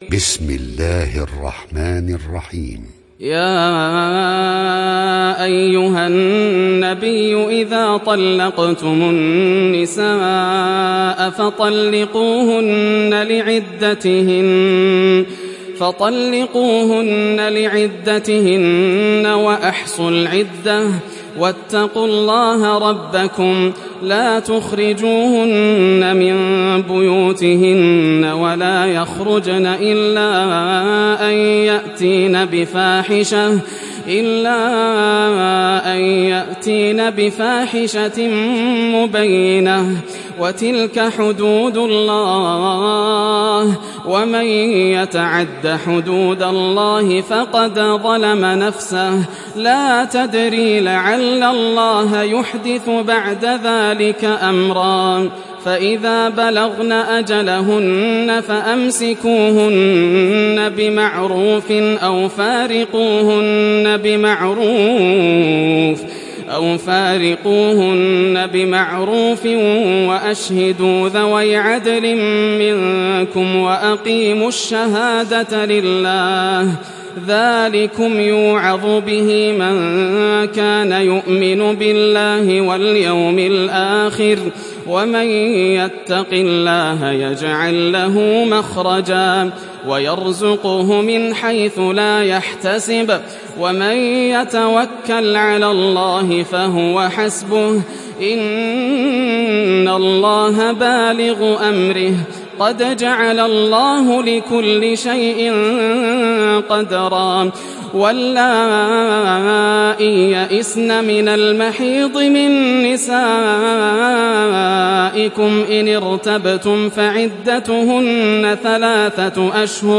دانلود سوره الطلاق mp3 ياسر الدوسري (روایت حفص)